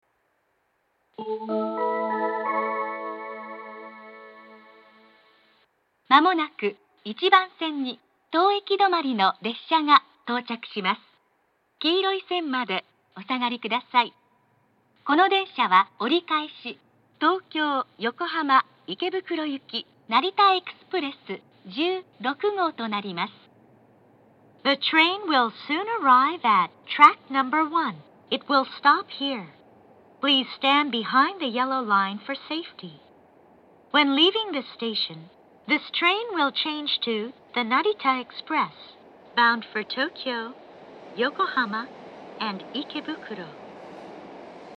メロディーは成田空港２駅のオリジナルのものを使用しており、藤沢４番線の曲に似ています。
１番線接近放送 折り返し成田エクスプレス１６号東京・横浜・池袋行の放送です。
１番線発車メロディー 発車放送は成田エクスプレス１２号東京・横浜・新宿行です。
narita-airport-1bannsenn-sekkinn1.mp3